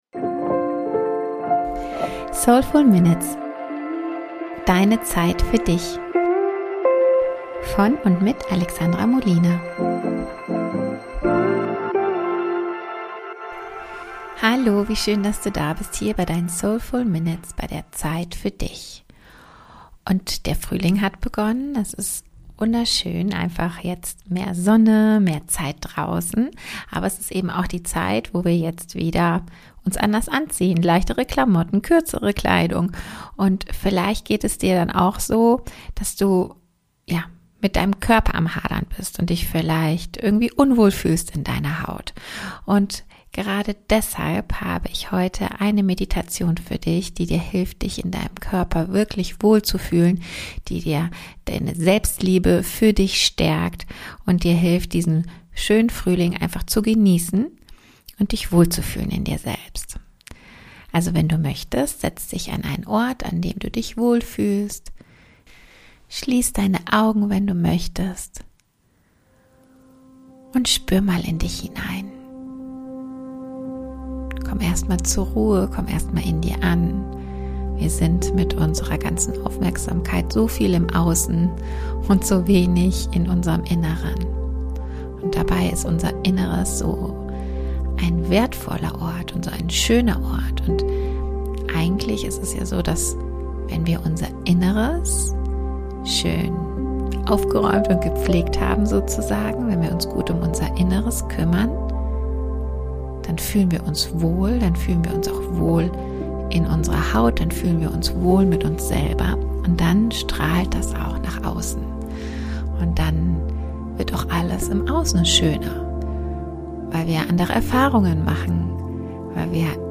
Ich fühl mich wohl in meinem Körper – Meditation zur Stärkung der Selbstliebe